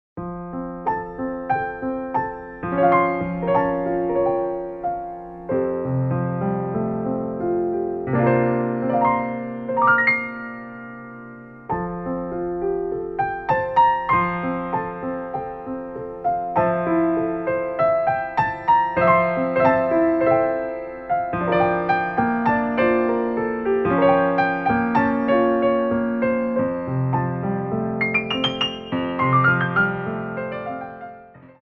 Ports de Bras 2
4/4 (8x8)